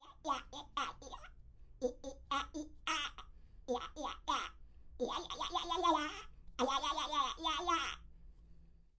描述：模仿鸭子或企鹅的高速度
Tag: 企鹅 鸭子